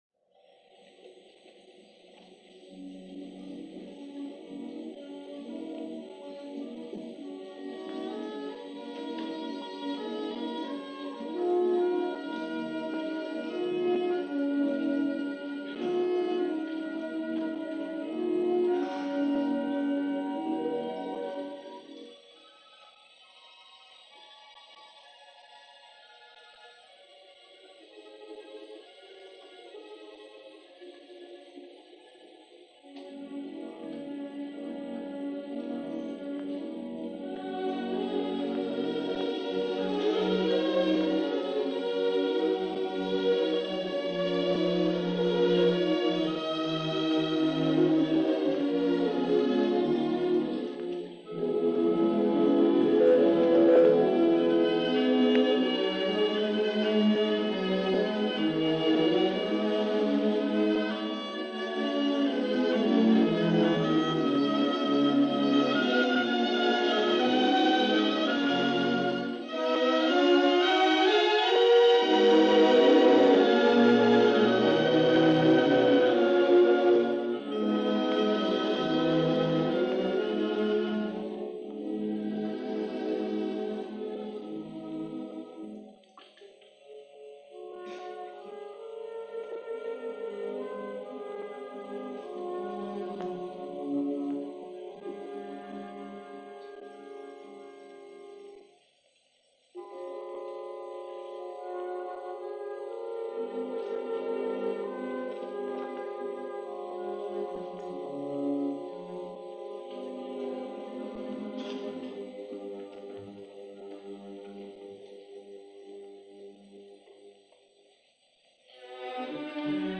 . or click on the link here for Audio Player – Paul Le Flem – Adagio – Paris Conservatory Orch. Andrè Cluytens, cond, – 1949 Besançon Festival – Gordon Skene Sound Collection
His Adagio, written in 1943 and given its first performance during the 1949 Besançon Festival with the Paris Conservatory Orchestra conducted by André Cluytens.
So presumably, this is the only known recording of this sprawling and evocative work.
Apologies for the sound quality in spots – rare and damaged is a bad combinations.